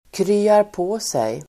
Ladda ner uttalet
krya på sig verb, recover Grammatikkommentar: A & Uttal: [kry:arp'å:sej] Böjningar: kryade på sig, kryat på sig, krya på sig, kryar på sig Definition: bli frisk Exempel: krya på dig!